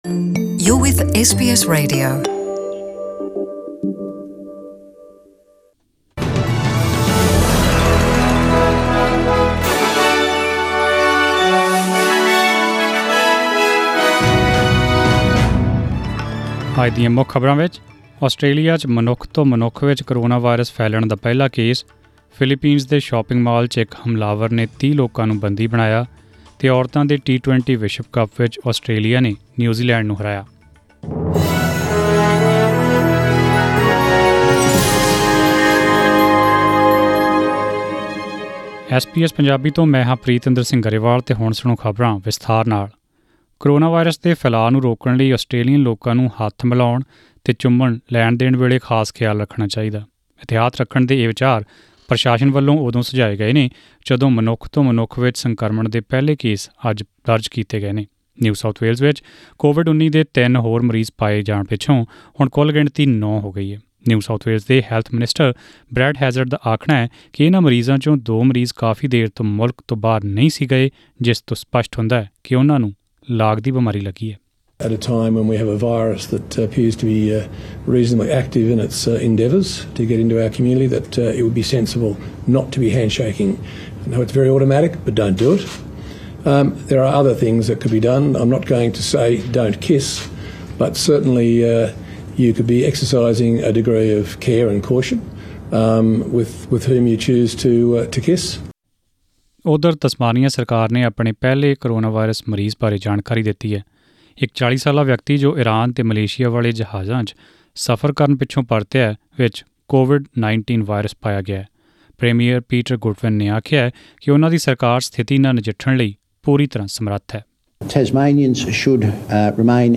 In this bulletin -